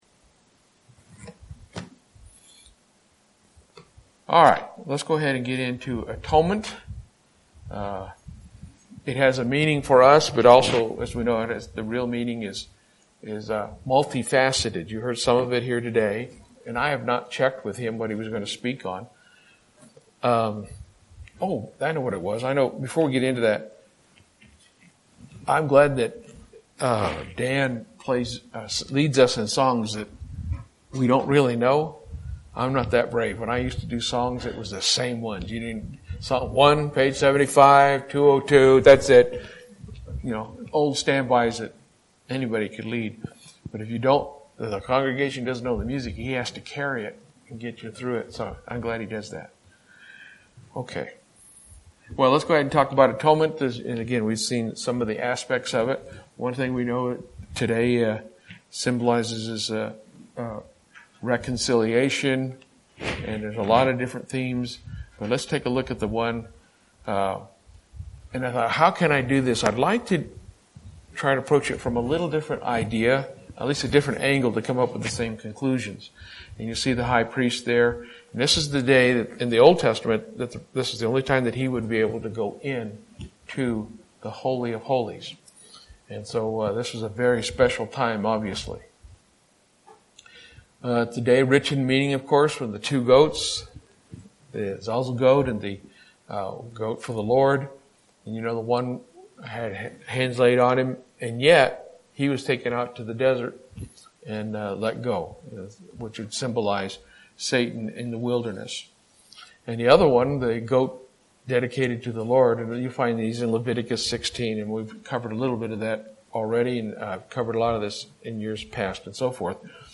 Given in Lubbock, TX
UCG Sermon Studying the bible?